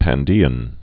(păn-dēən)